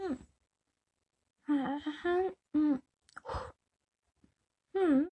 描述：撕纸
Tag: 翻录 撕裂 撕裂纸 切口 撕裂